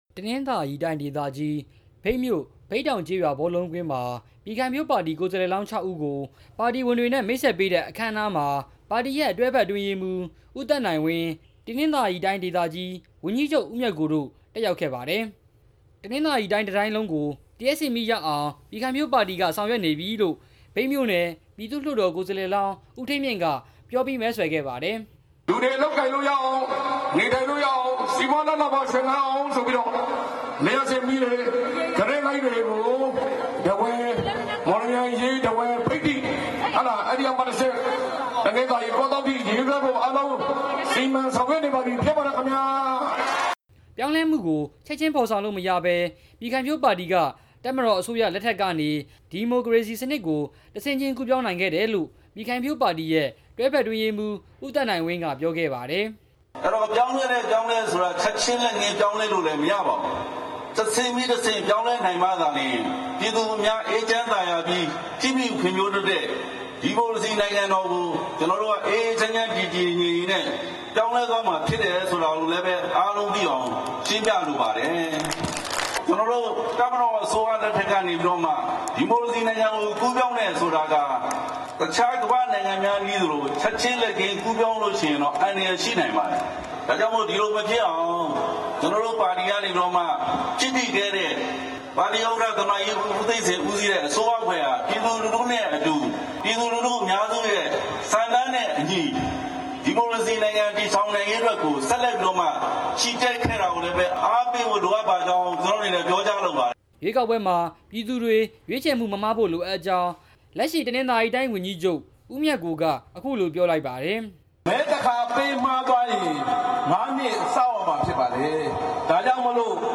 မြိတ်မြို့နယ်က ပြည်ခိုင်ဖြိုးပါတီ မဲဆွယ်စည်းရုံးတဲ့အကြောင်း တင်ပြချက်